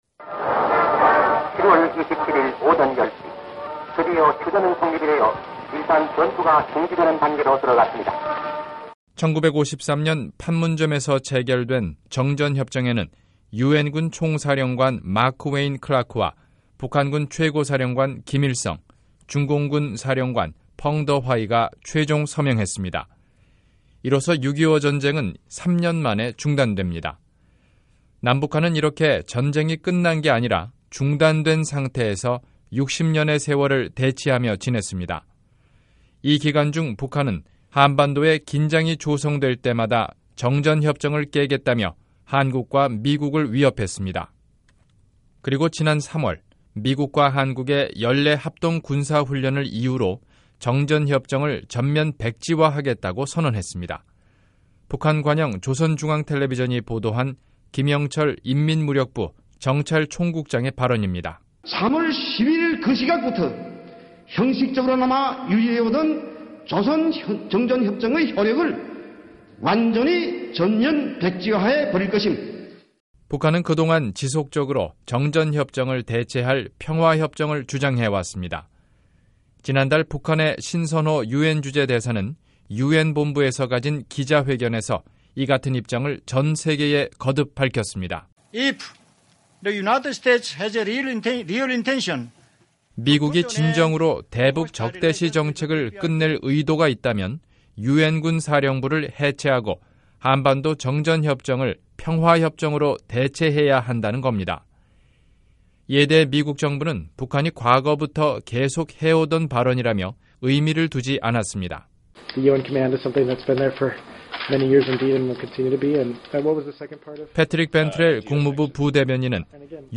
[기획보도: 한국전 정전 60주년] 3. 평화협정의 걸림돌